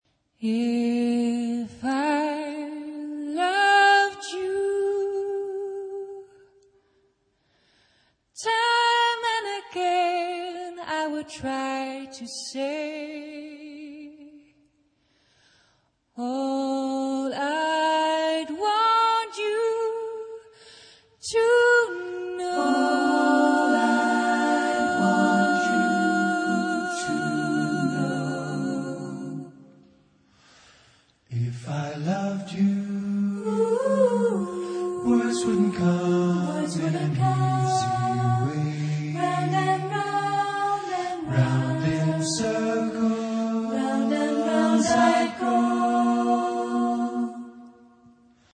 SATB (4 voices mixed).